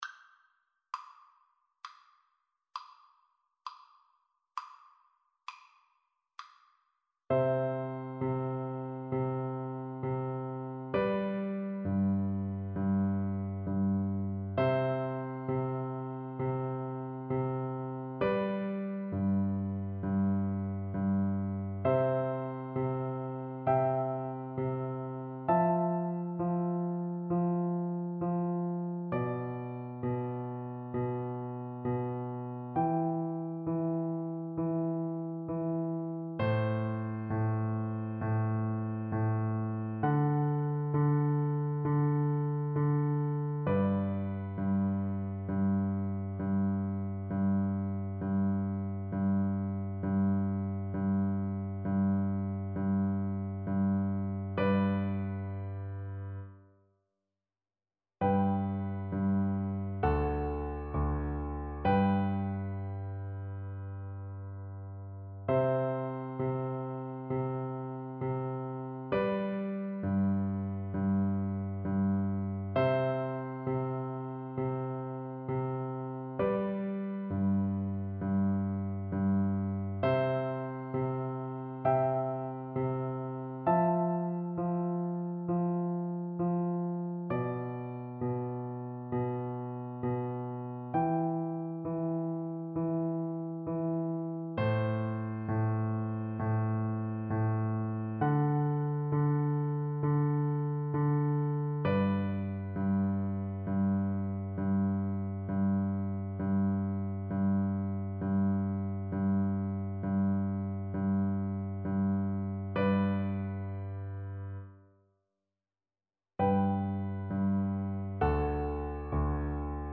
Soprano (Descant) Recorder version
Largo =33
4/4 (View more 4/4 Music)
Classical (View more Classical Recorder Music)